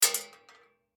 shotgun_metal_2.ogg